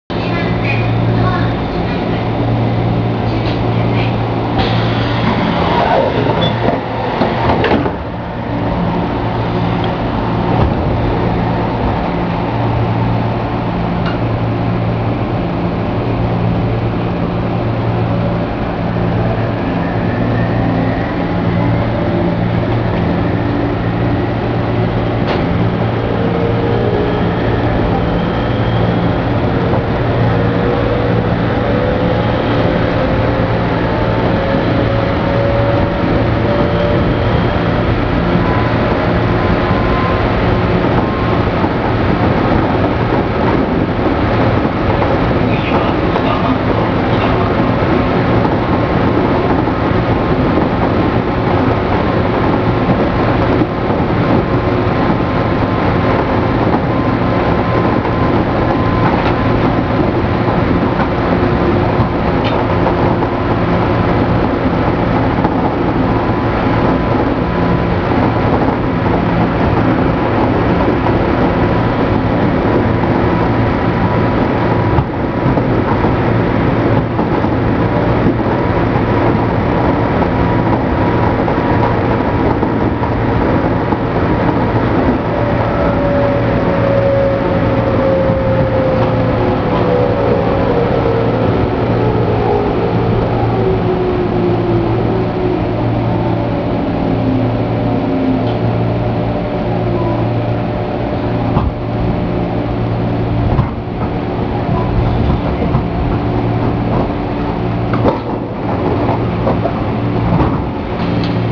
・203系走行音
【常磐線】馬橋〜北松戸（1分51秒：606KB）
車体が時々ミシミシ音を立てているのはやはり劣化が進んでいる証拠なのでしょうか。基本的に、音は201系と全く同じですが、走行中はアルミ車体故か、軽いドアが揺れに揺れてしまっています。